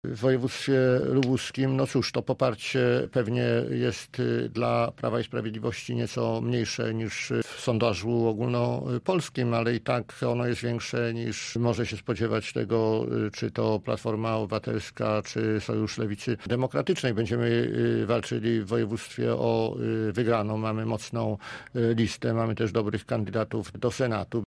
Będziemy ciężko pracować na pozytywny werdykt – mówił przewodniczący lubuskich struktur partii w Rozmowie Punkt 9:
Cała rozmowa z posłem Markiem Astem do wysłuchania na stronie Radia Zielona Góra.